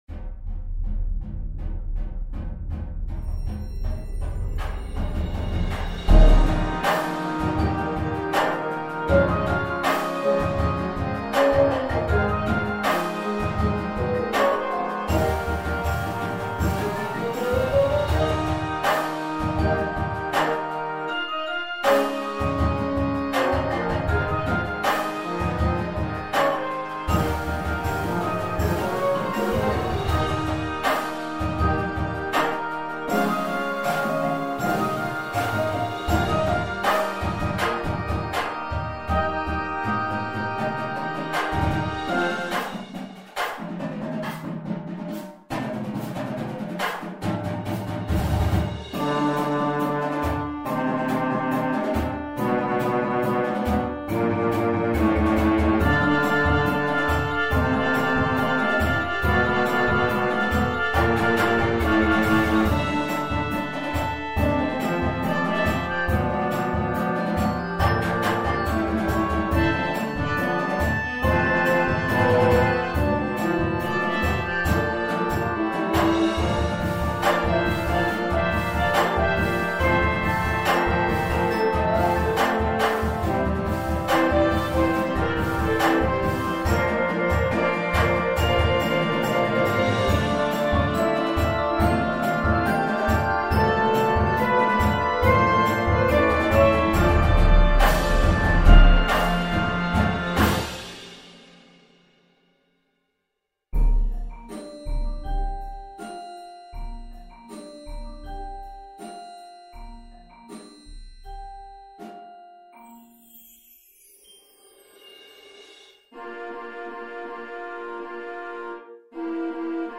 This was really my berth into writing percussion ensemble-style music for marching band.